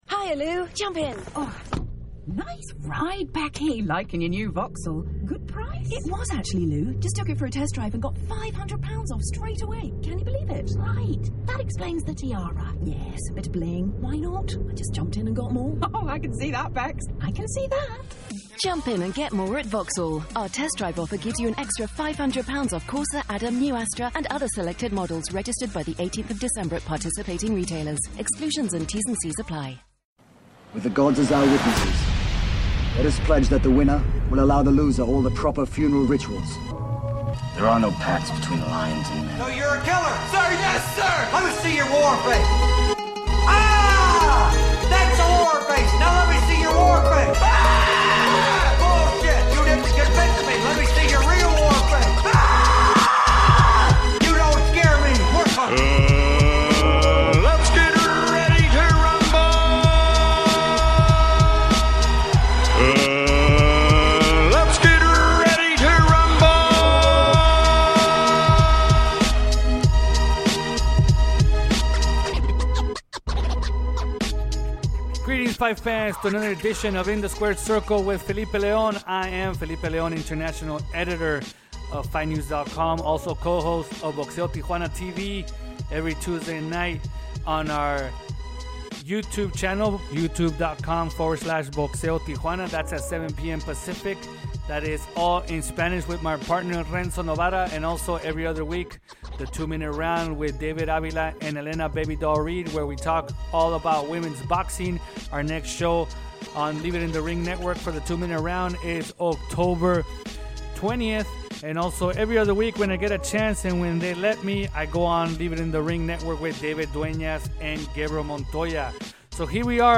passes the latest news in the sport with a fast pace style of 30 minutes or less.